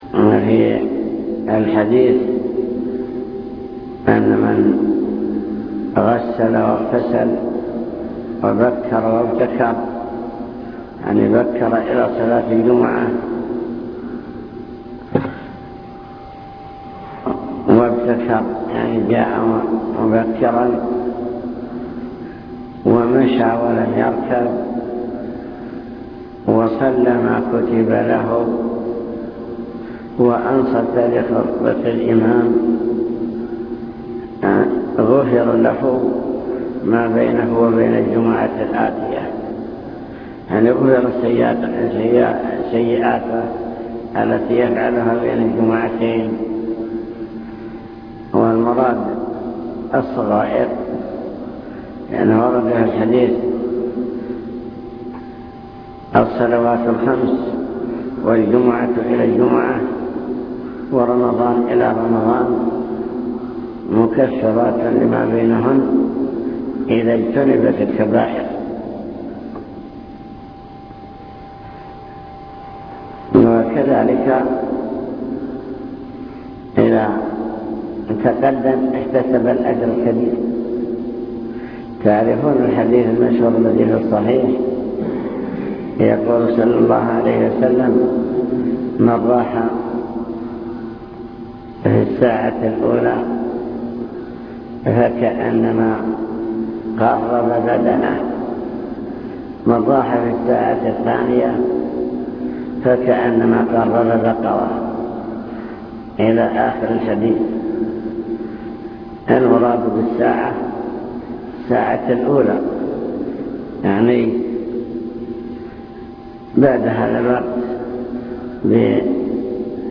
المكتبة الصوتية  تسجيلات - محاضرات ودروس  درس في الجمعة مع بلوغ المرام الحث على الخشوع في الصلاة